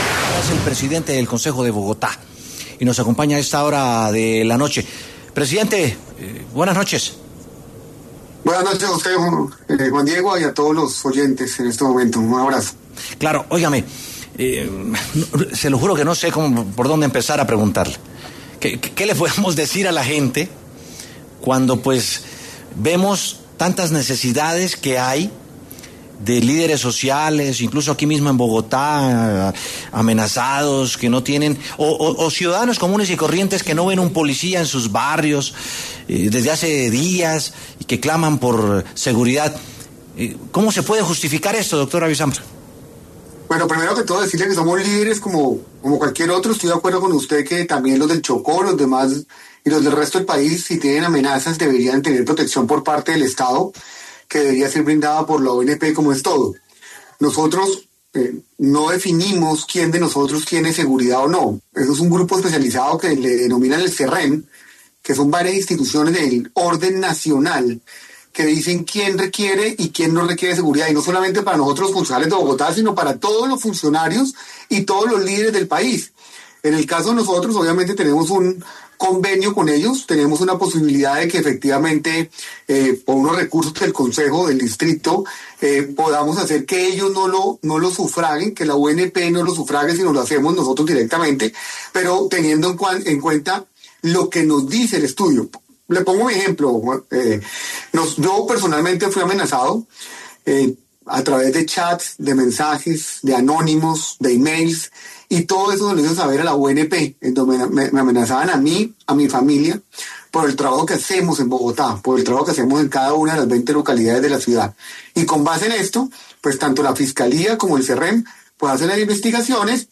Por esta razón, Samir José Abisambra Vesga, presidente del Concejo de Bogotá, pasó por los micrófonos de W Sin Carreta, para pronunciarse al respecto.